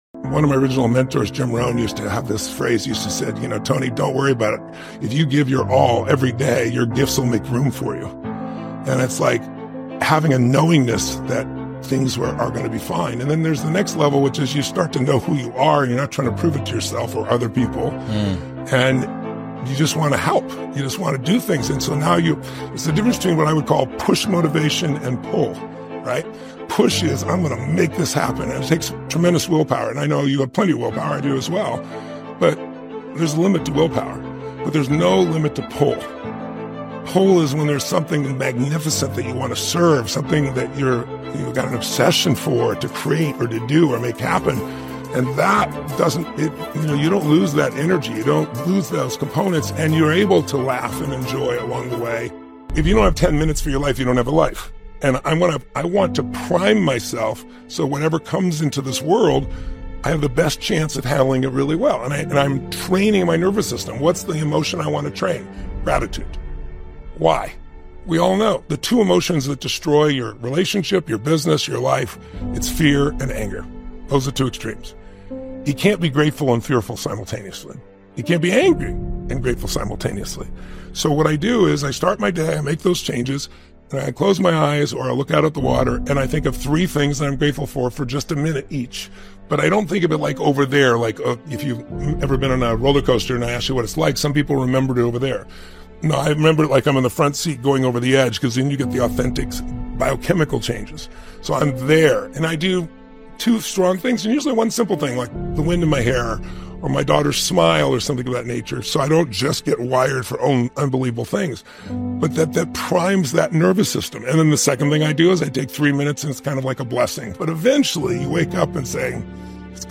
Tony Robbins - Give your all everyday motivational speech